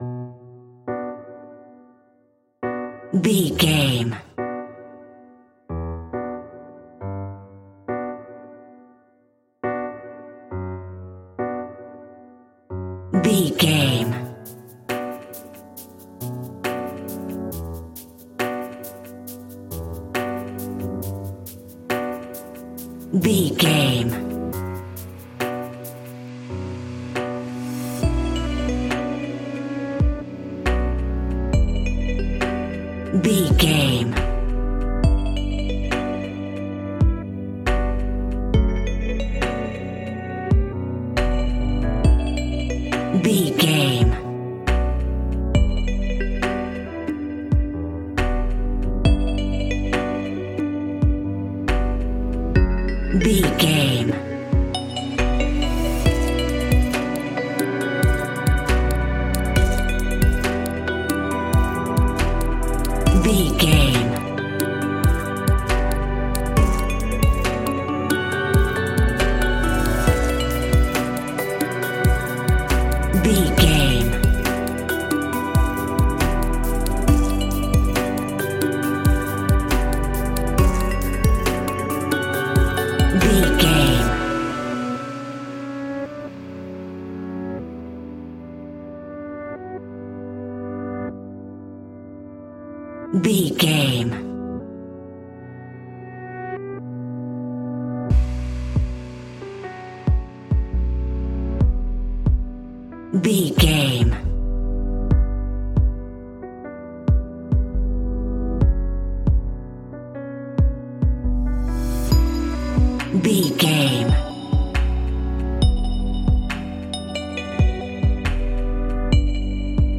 Aeolian/Minor
groovy
dreamy
tranquil
smooth
drum machine
synthesiser
house
instrumentals
synth bass